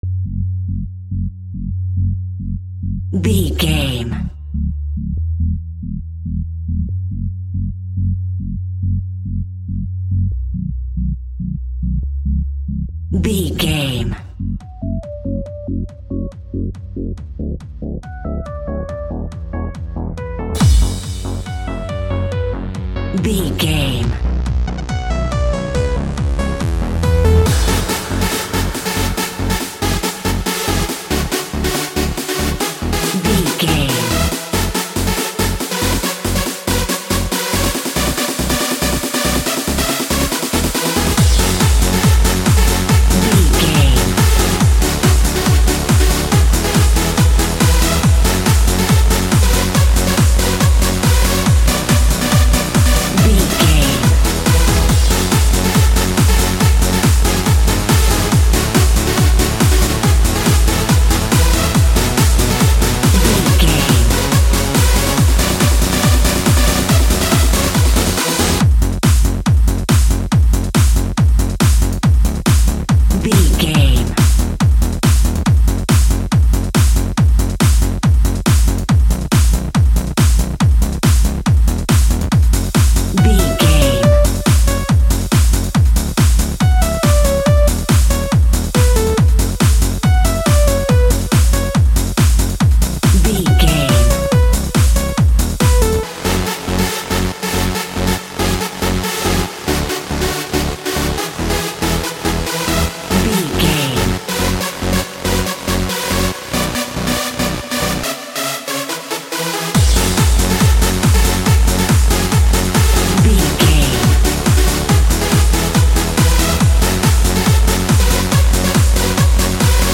Aeolian/Minor
F#
Fast
energetic
hypnotic
uplifting
synthesiser
drum machine
acid house
uptempo
synth leads
synth bass